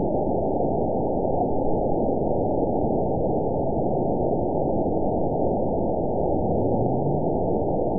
event 911201 date 02/17/22 time 04:26:39 GMT (3 years, 3 months ago) score 8.82 location TSS-AB01 detected by nrw target species NRW annotations +NRW Spectrogram: Frequency (kHz) vs. Time (s) audio not available .wav